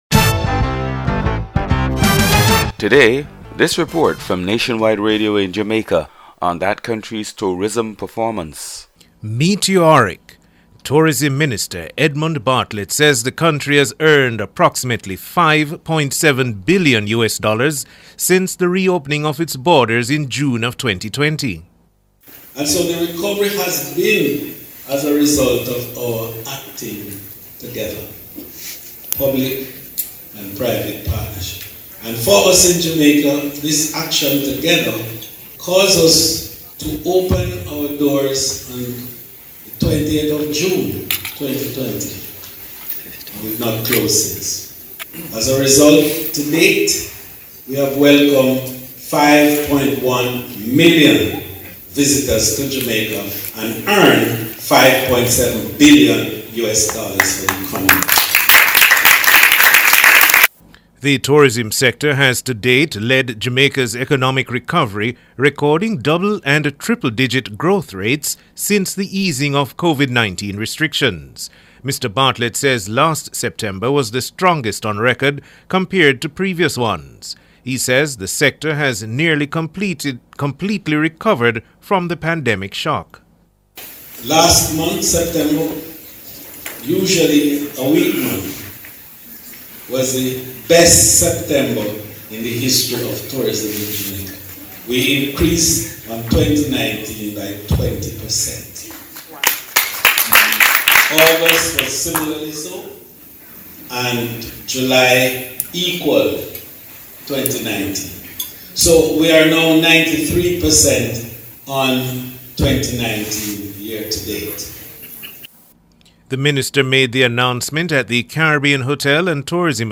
Reports from Nationwide Radio in Jamaica on that country's tourism performance.